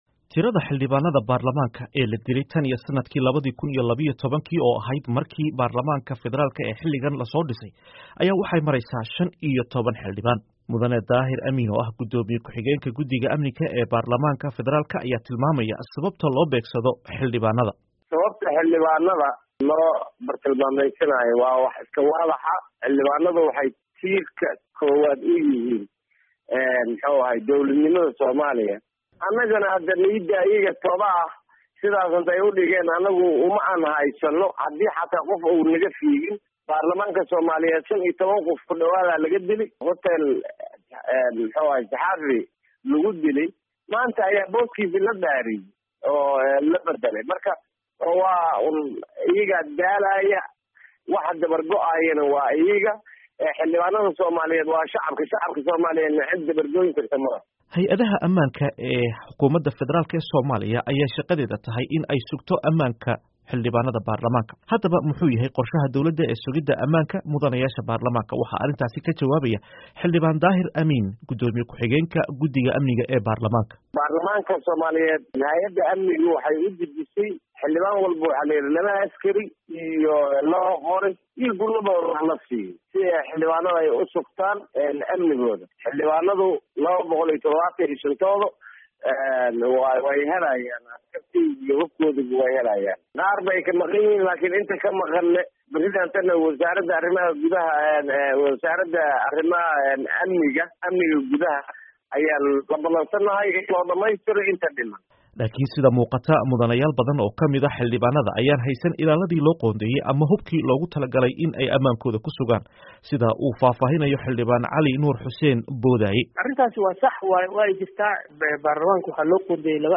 Warbixin: Weerarada Xildhibaanada Somalia